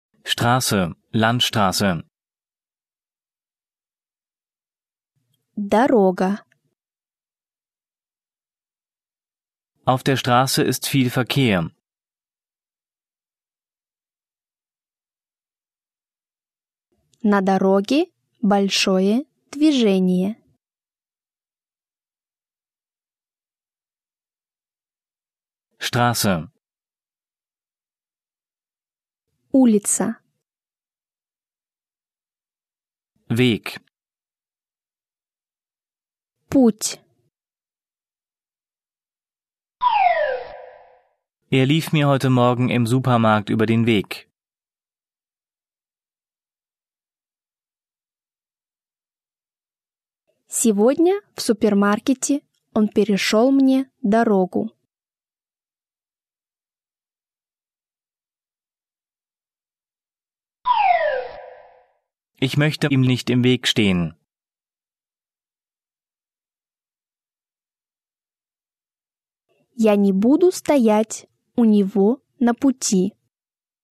Zweisprachiger Aufbau (Deutsch - Fremdsprache)
von Muttersprachlern gesprochen
mit Übersetzungs- und Nachsprechpausen
Er ist zweisprachig aufgebaut (Deutsch - Russisch), nach Themen geordnet und von Muttersprachlern gesprochen.